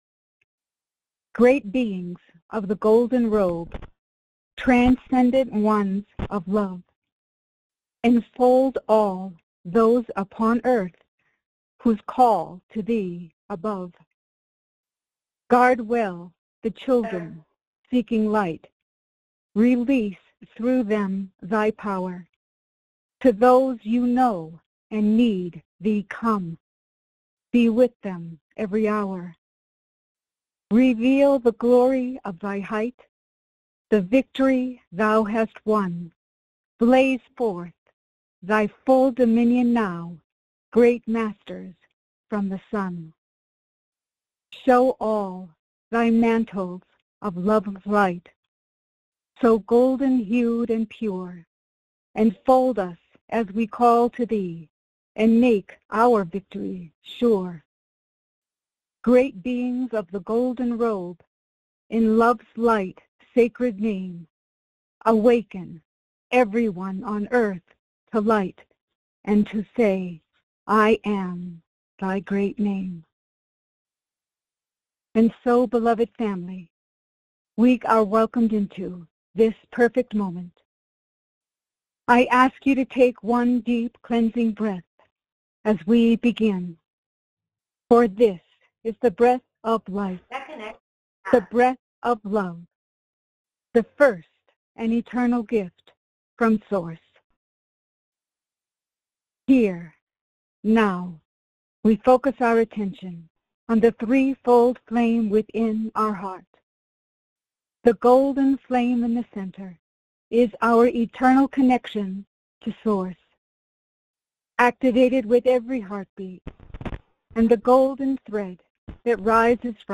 Follow along in group meditation with Lord Sananda /(Jesus).